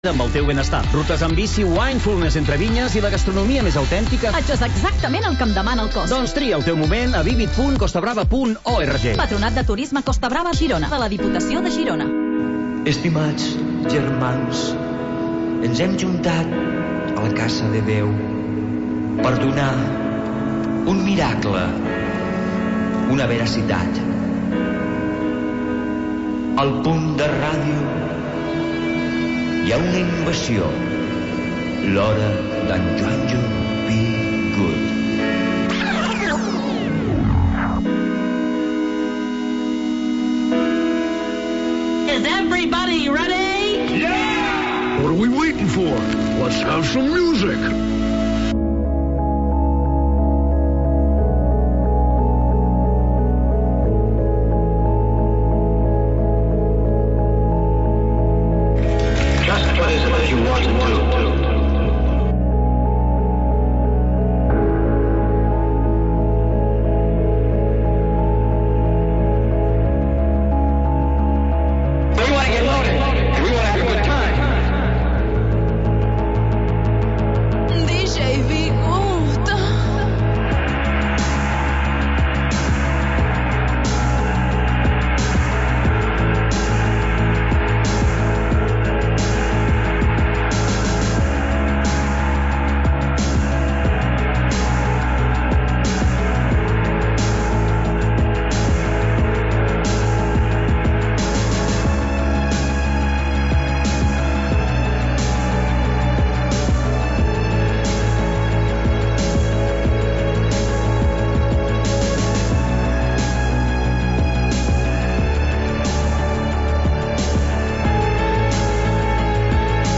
Selecció musical independent